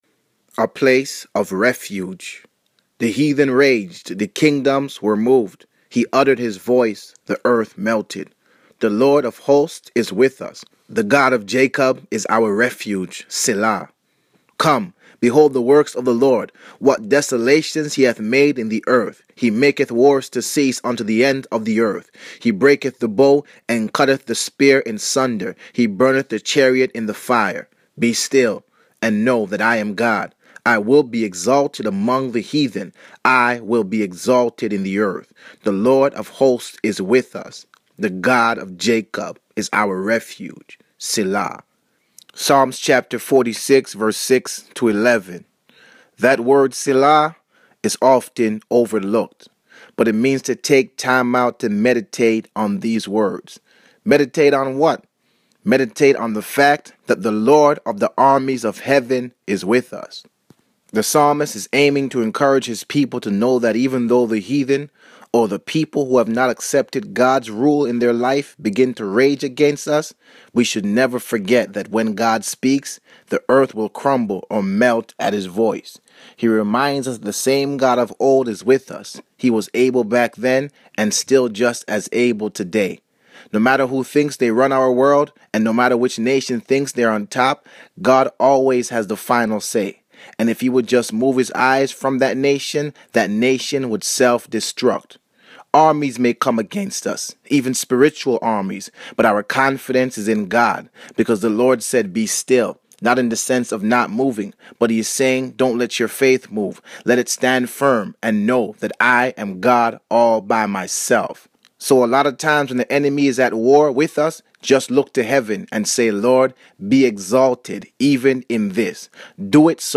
Today's Audio Devotion